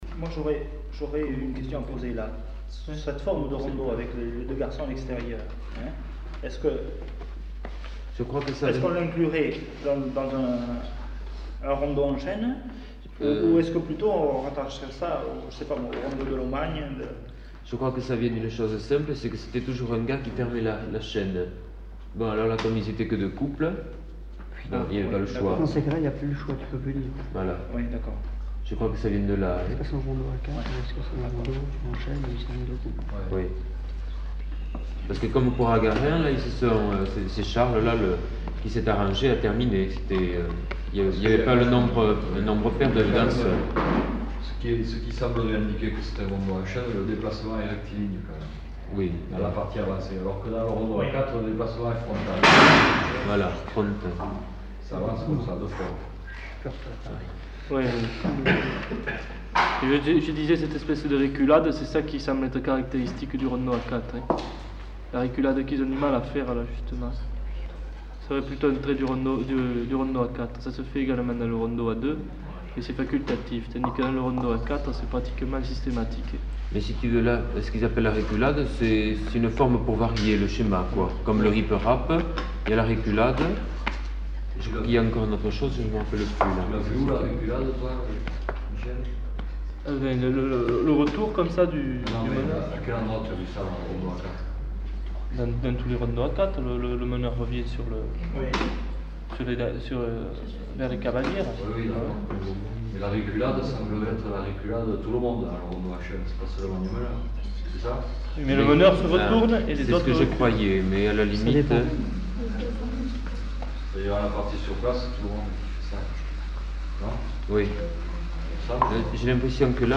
Type de son : mono
Qualité technique : moyen
L'enregistrement consiste en une séance de travail regroupant plusieurs collecteurs issus de différentes associations (ACPA, ACPL, Conservatoire occitan, Lo Pifre) et se déroulant autour de la projection de plusieurs films de collectage réalisés auprès de danseurs de rondeau dans les départements des Landes et du Lot-et-Garonne. Plusieurs questions sont abordées : les pas de différentes variantes, la forme du rondeau en chaîne et sa différence avec le rondeau à quatre, le meneur de la chaîne, le rôle du chant et celui de la musique instrumentale dans l'évolution de la danse.
Notes consultables : Bruits de projection (film).